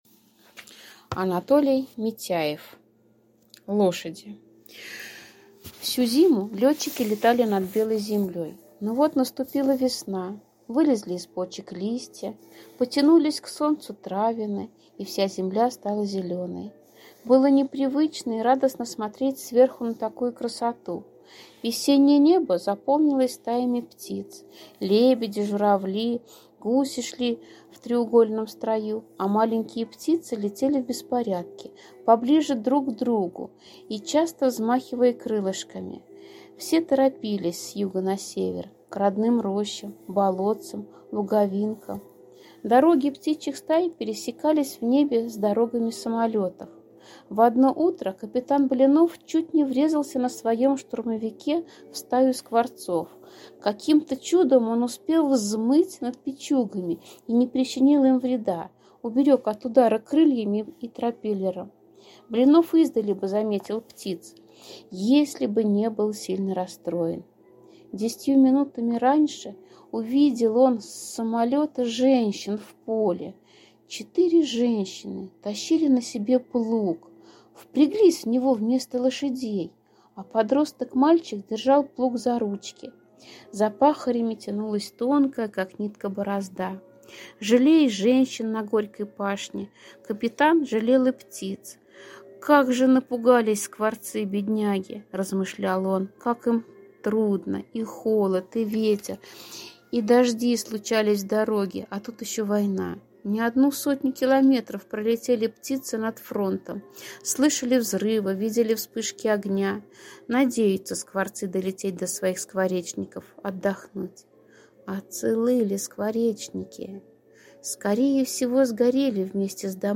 Аудиорассказ «Лошади»